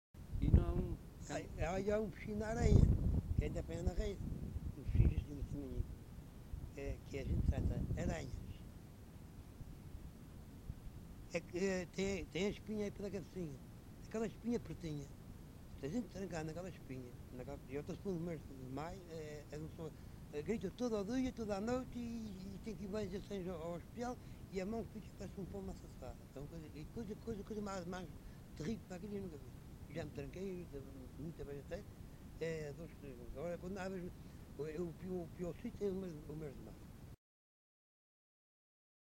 LocalidadePraia da Vitória (Praia da Vitória, Angra do Heroísmo)